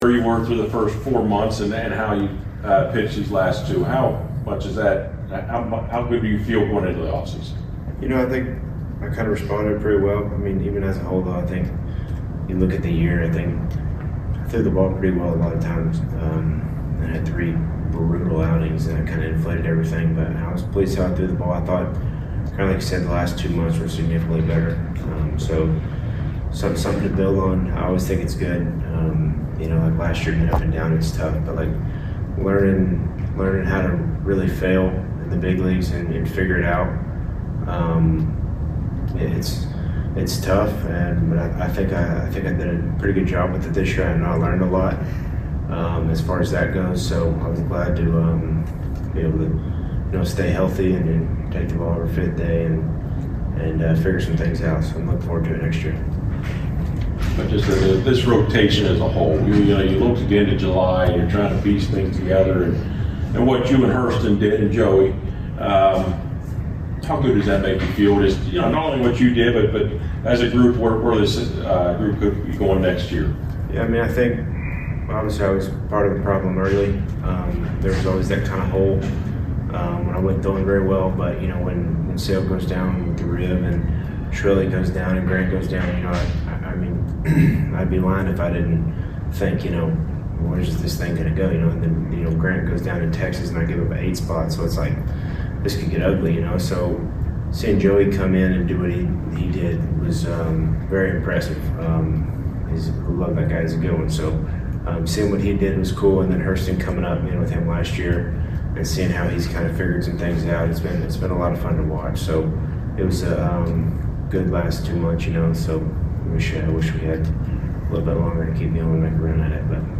Atlanta Braves Pitcher Bryce Elder Postgame Interview after losing to the Washington Nationals at Truist Park.